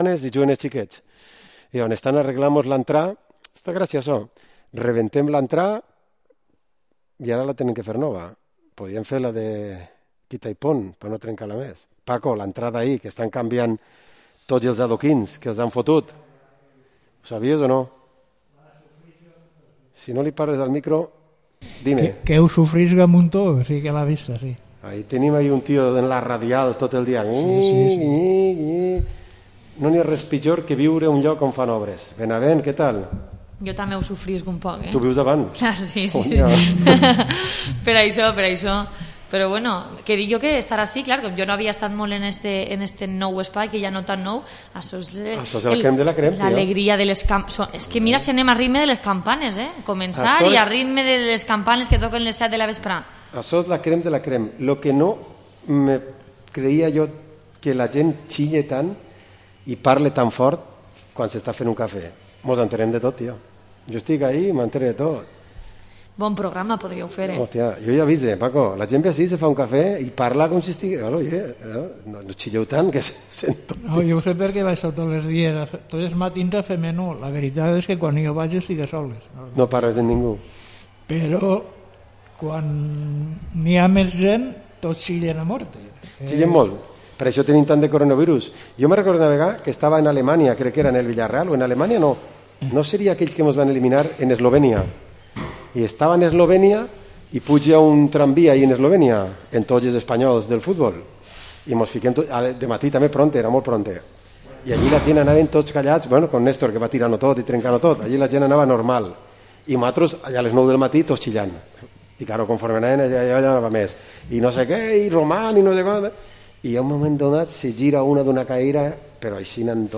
Tertulia Grogueta Radio Vila-real 5 d’ Octubre 2020